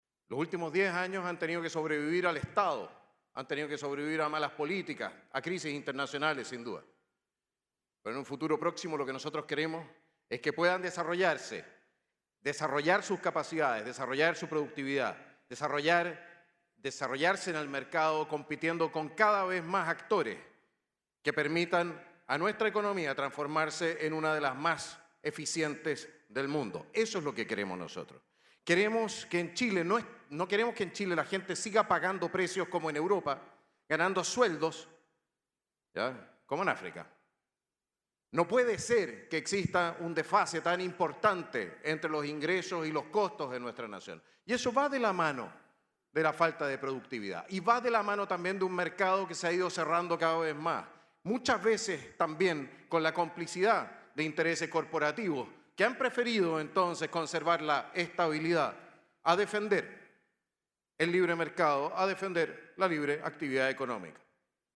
Finalmente, el candidato Johannes Kaiser, del Partido Nacional Libertario, indicó que mejorará la productividad de las empresas abriendo el mercado a más actores “que permitan a nuestra economía transformarse en una de las más eficientes del mundo”.